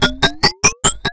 PHASER LP1-L.wav